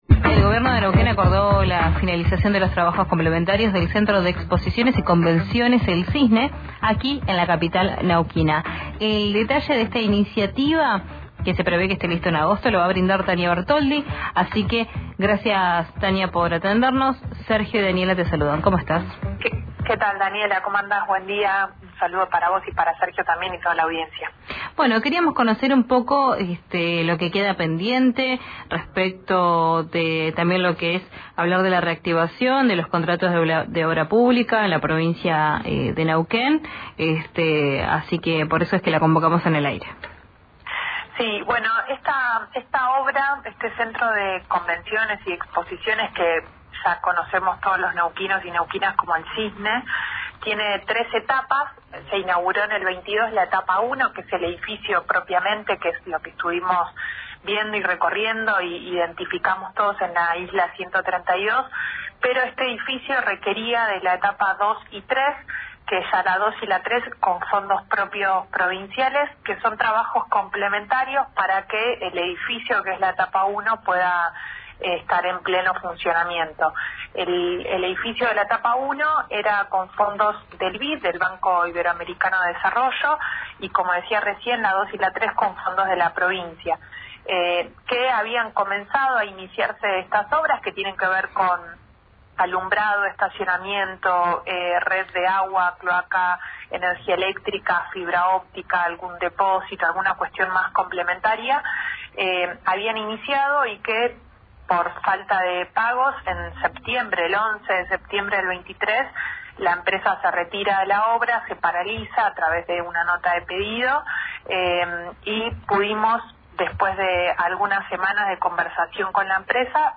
La intendenta Silvia Penilla habló este lunes con RÍO NEGRO RADIO y reveló detalles sobre el cronograma aniversario.